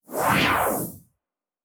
pgs/Assets/Audio/Sci-Fi Sounds/Movement/Synth Whoosh 1_5.wav at master
Synth Whoosh 1_5.wav